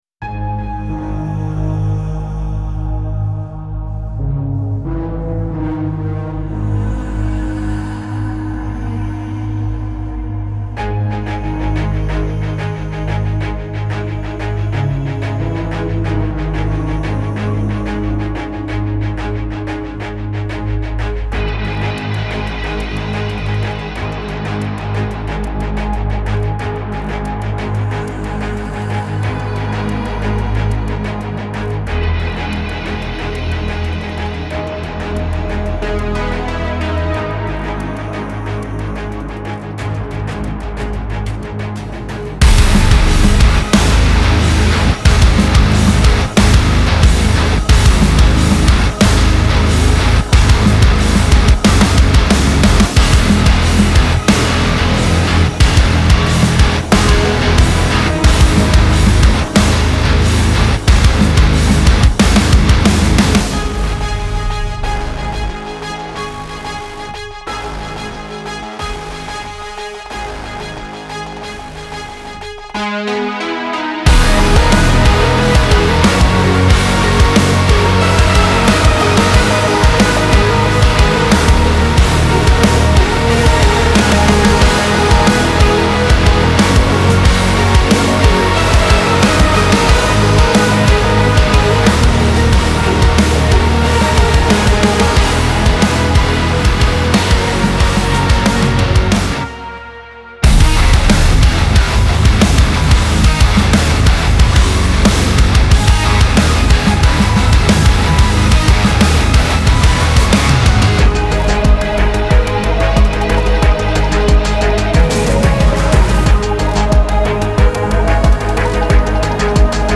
Genre : Hard Rock